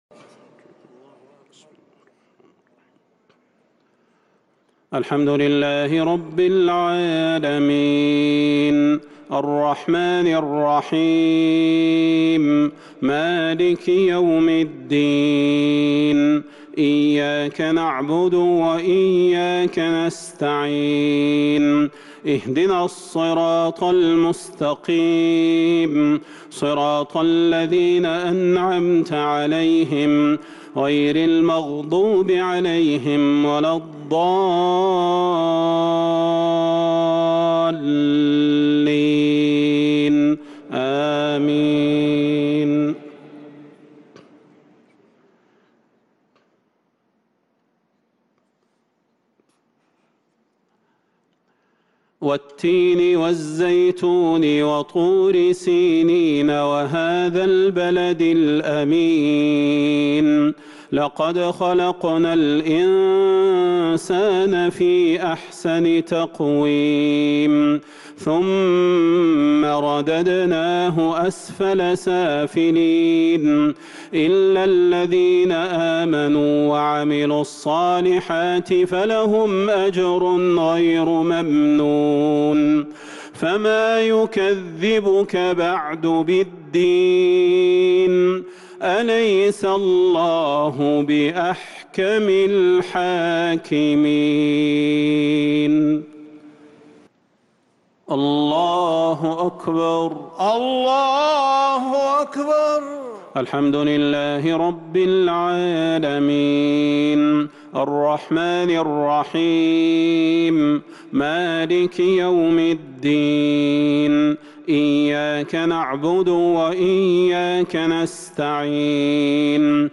عشاء الخميس 6-9-1443هـ سورتي التين و القدر | isha prayer Surah At-Tin and Al-Qadr 7-4-2022 > 1443 🕌 > الفروض - تلاوات الحرمين